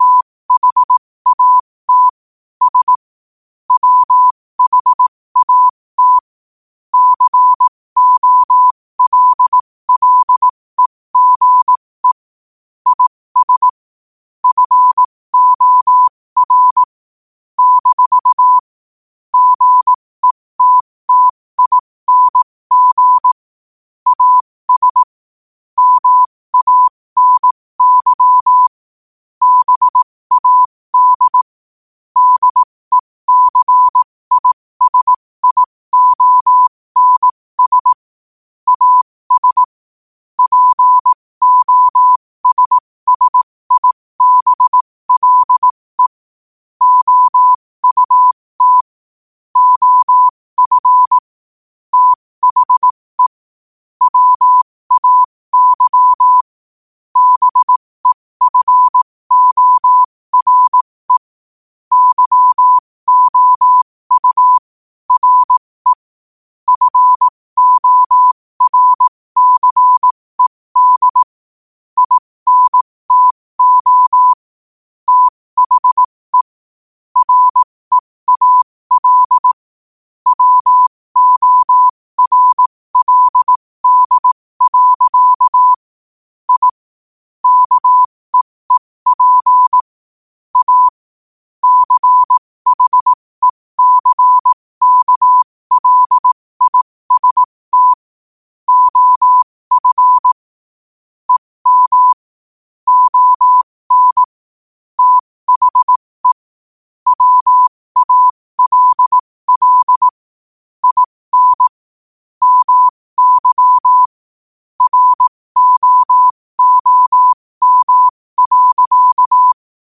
New quotes every day in morse code at 15 Words per minute.